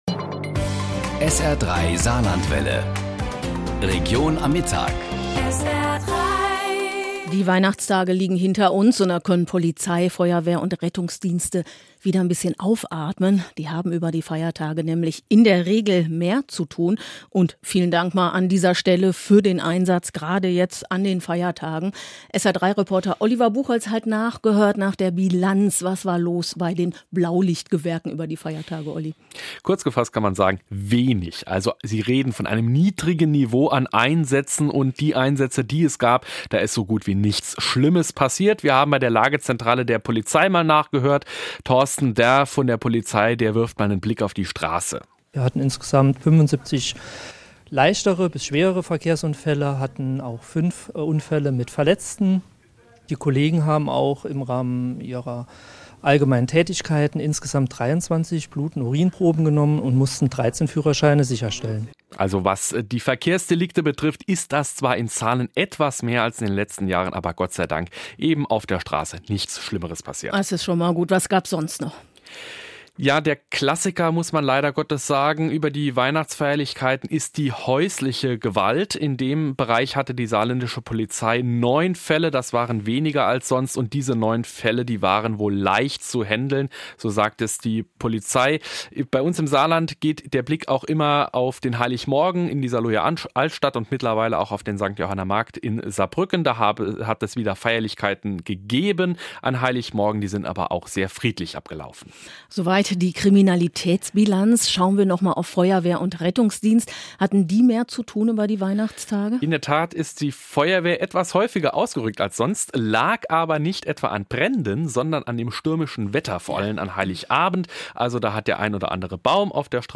Berichte, Reportagen und Hintergründe zu aktuellen Ereignissen in der Saar-Lor-Lux-Region